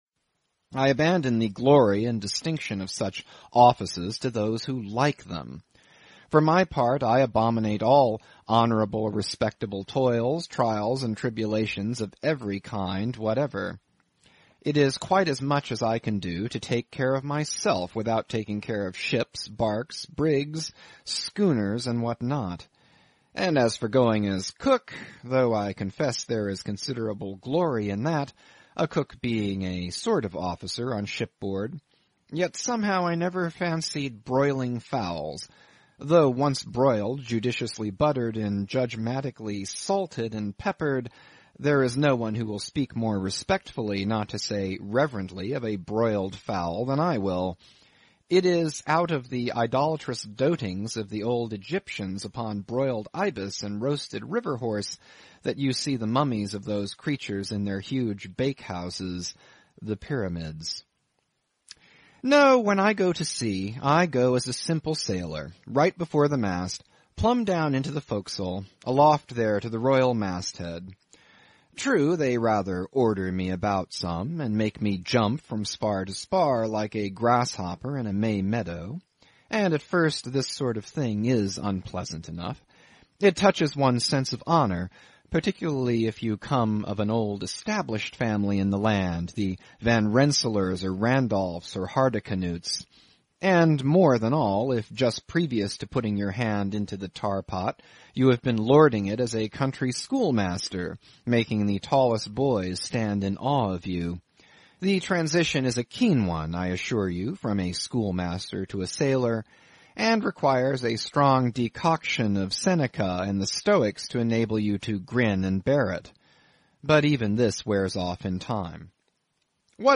英语听书《白鲸记》 第5期 听力文件下载—在线英语听力室